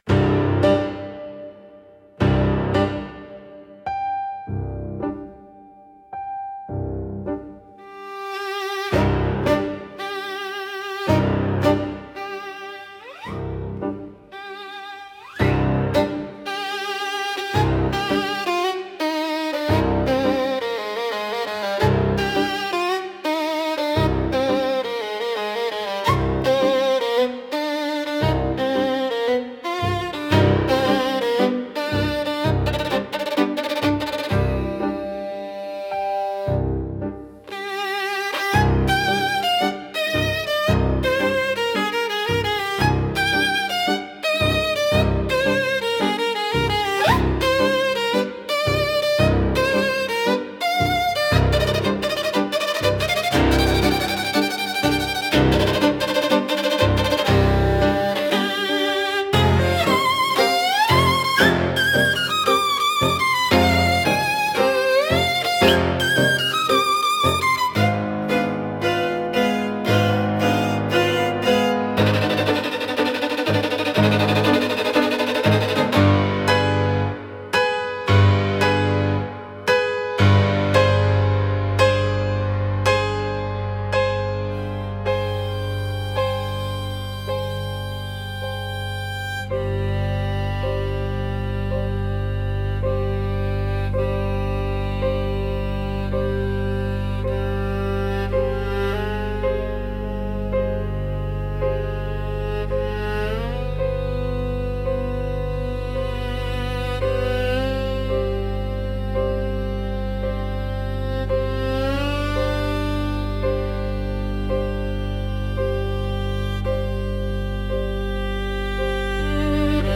Авангардное экспрессионистское кабаре в стиле Скрябина